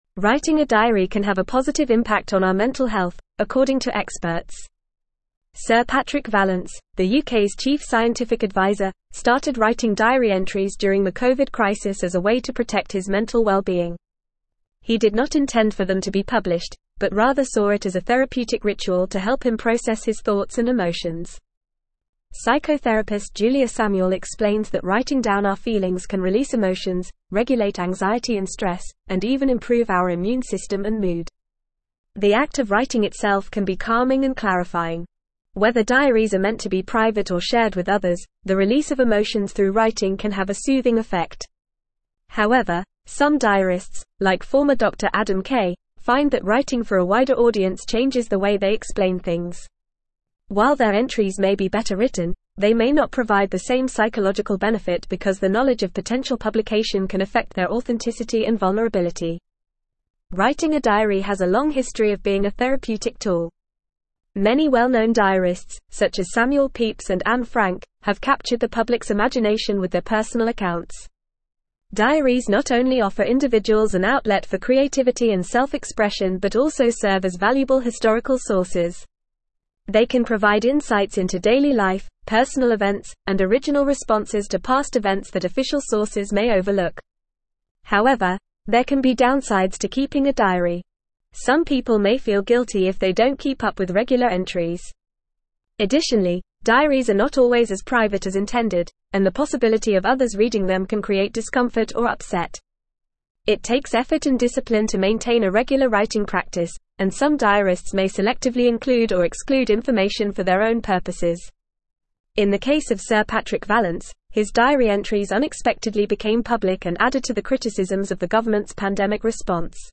Fast
English-Newsroom-Advanced-FAST-Reading-The-Therapeutic-Benefits-of-Writing-a-Diary.mp3